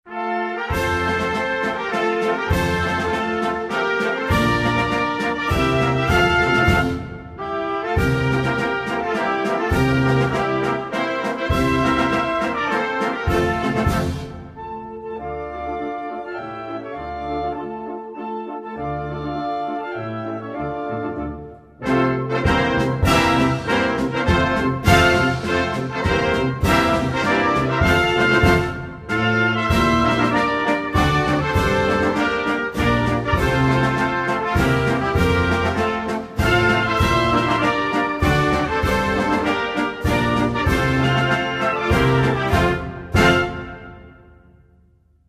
Hymn_to_liberty_instrumental.mp3